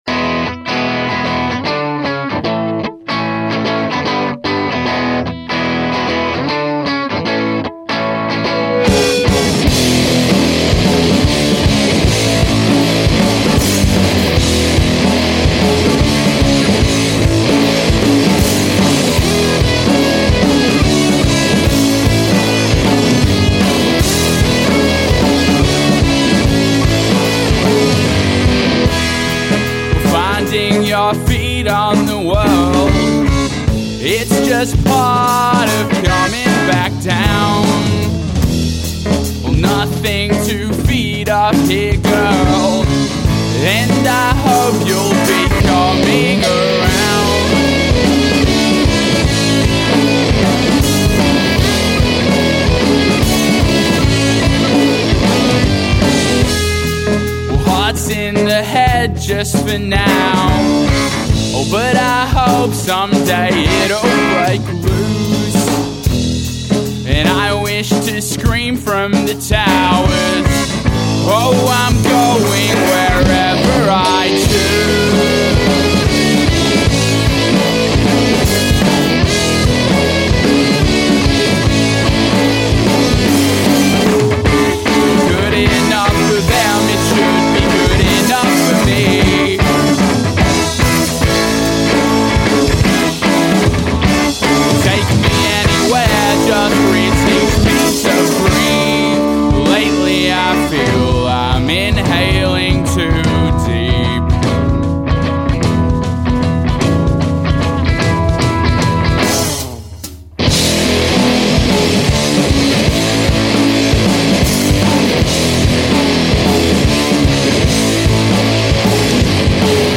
We also feature two singer songwriters for the first time at the show.